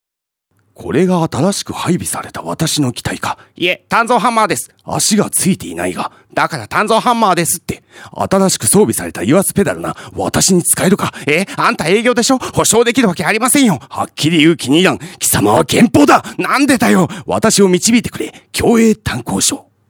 ラジオCM制作
有名アニメキャラクターを彷彿させる語り口で、壮大な世界観をラジオCMで表現。